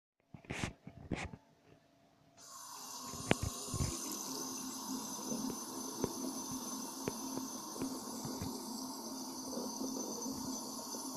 Abriendo el grifo del agua
Grabación sonora que capta el sonido del momento en que alguien abre el grifo del agua y ésta comienza a salir por el mismo.
Sonidos: Agua
Sonidos: Acciones humanas